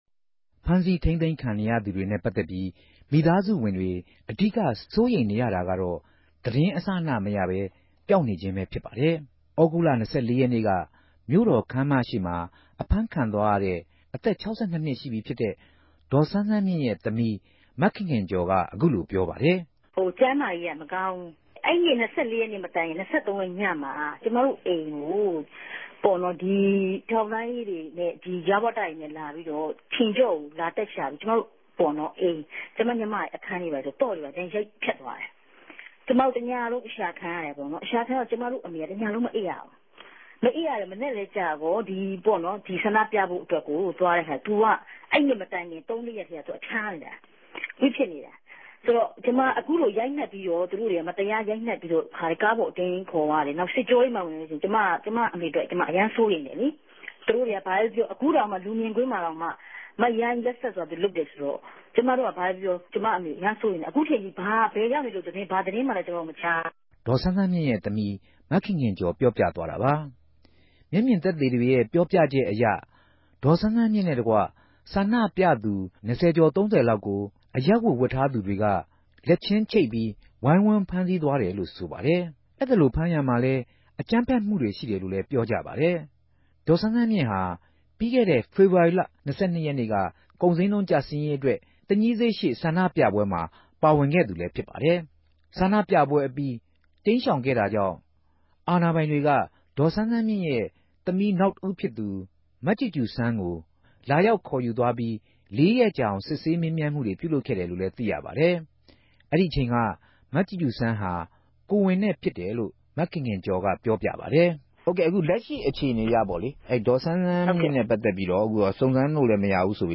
အဖမ်းခံရသူတေရြဲ့ မိသားစုတေနြဲႛ ဆက်သြယ် မေးူမန်းထားပၝတယ်။